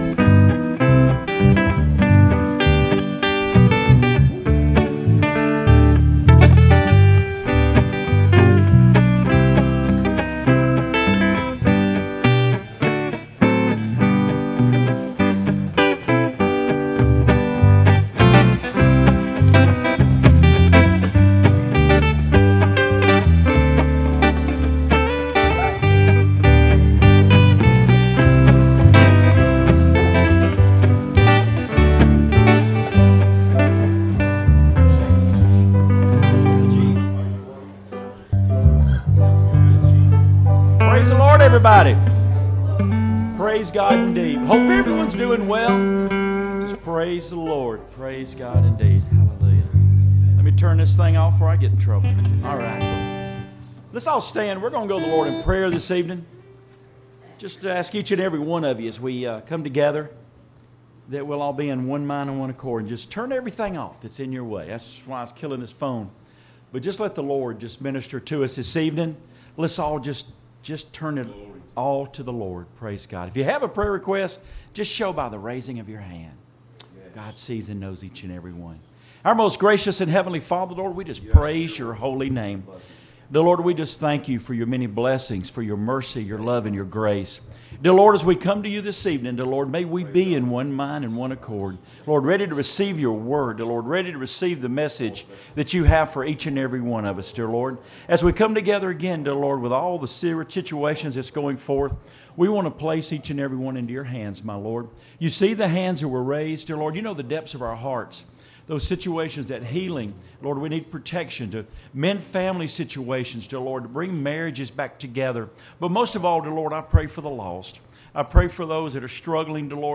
A Final Sermon